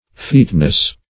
Featness \Feat"ness\, n. Skill; adroitness.